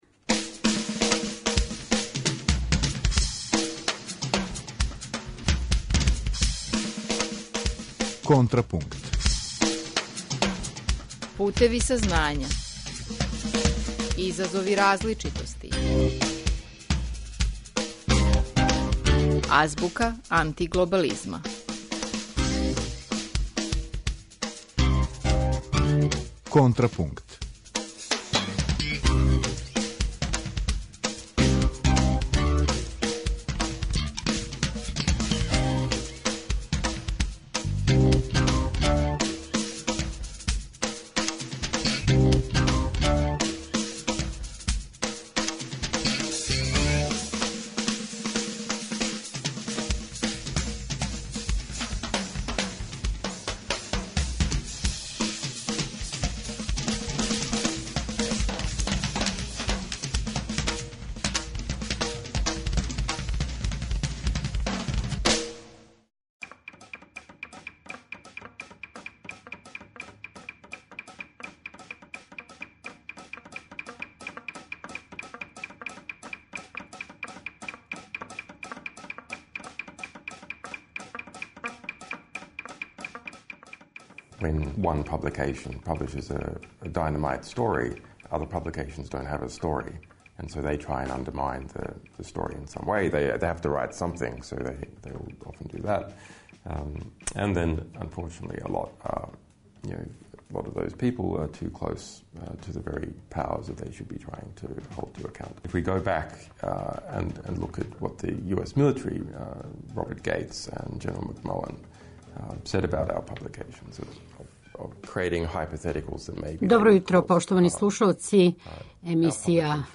У емисији Контрапункт, у разговору са